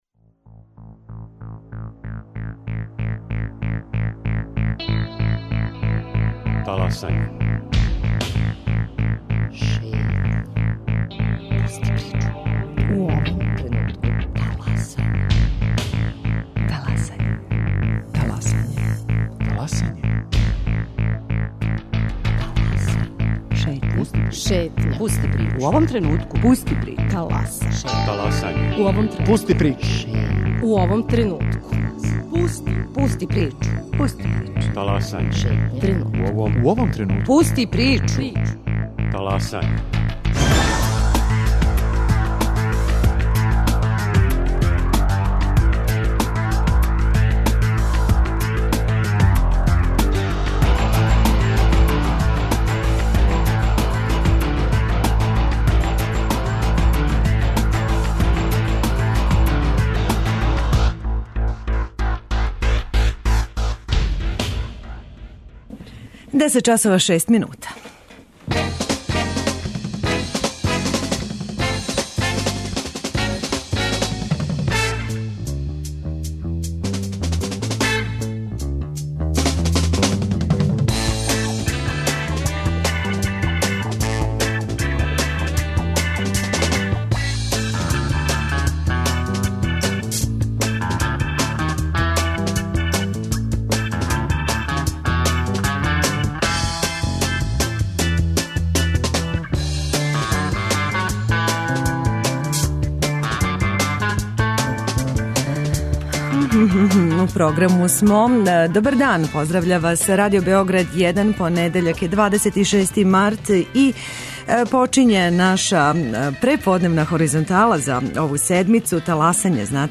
Наш гост је председник општине Нови Београд Ненад Миленковић, који ће нас упознати са првим бесплатним семинаром за незапослене са инвалидитетом.